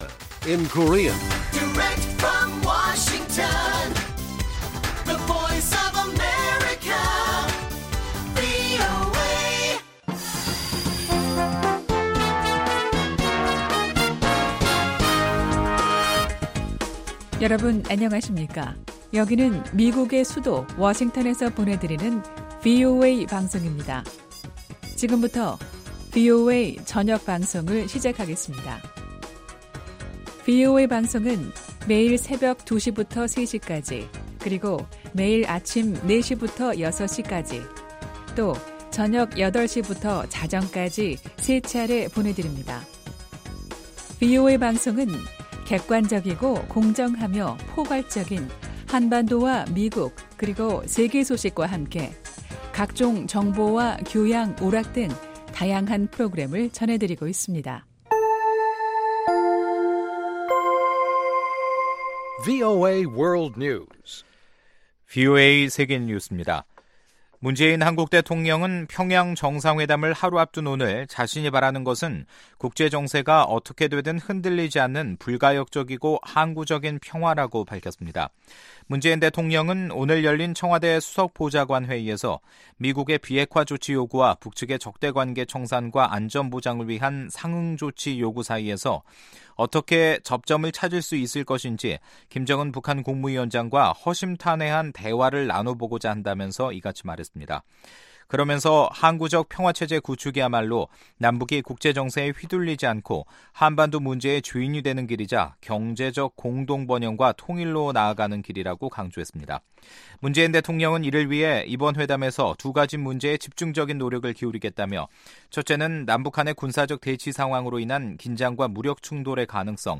VOA 한국어 간판 뉴스 프로그램 '뉴스 투데이', 2018년 9월 17일 1부 방송입니다. 문재인 한국 대통령이 김정은 북한 국무위원장과 회담하기 위해 18일 평양을 방문합니다. 북한 비핵화 문제가 사상 처음으로 남북정상회담 의제에 오릅니다.